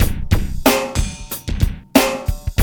BEAT 8 93 07.wav